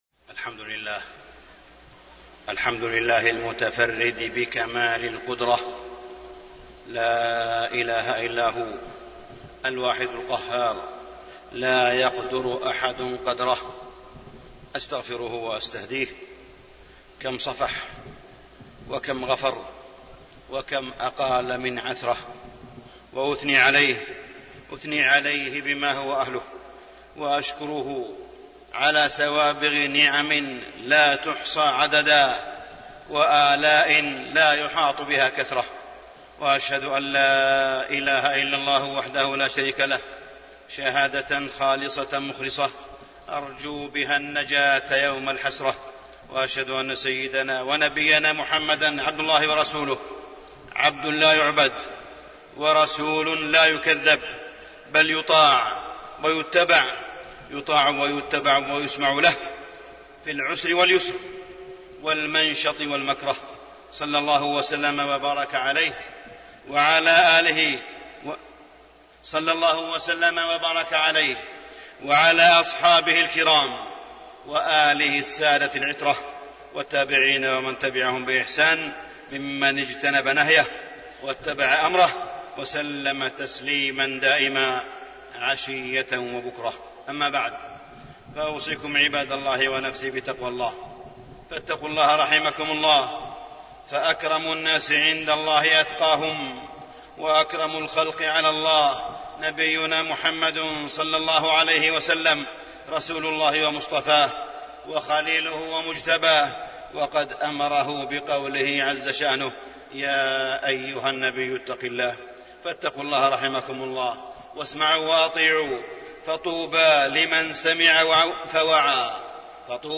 تاريخ النشر ١٤ شعبان ١٤٣٢ هـ المكان: المسجد الحرام الشيخ: معالي الشيخ أ.د. صالح بن عبدالله بن حميد معالي الشيخ أ.د. صالح بن عبدالله بن حميد معالم الهدى في أجواء الفتن